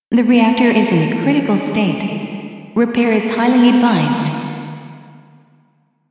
(The reverb and such will already be added to the samples and I will make sure it is as less seconds as possible while still keeping it understandable.)
I just found a pretty nice text-to-speech that has a really great voice that sounds perfect for broadcast-like recording :smiley:
What it gives so far with a Female voice.